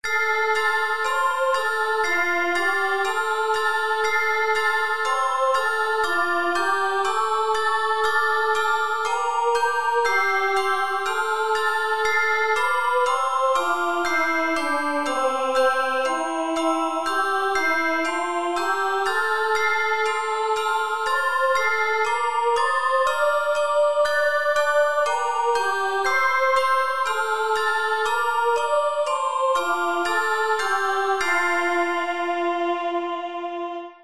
Descant for handbells.  3 octaves, 14 bells used.
Handbells/Handchimes 3 octaves, 14 bells used.